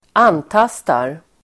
Uttal: [²'an:tas:tar]